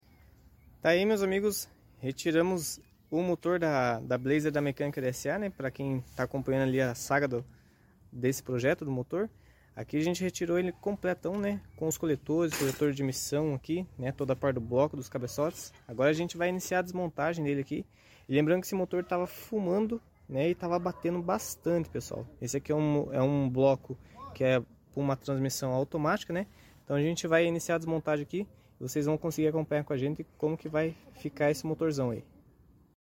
Desmontagem Motor Blazer 4.3 V6 sound effects free download